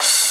cymbal03.ogg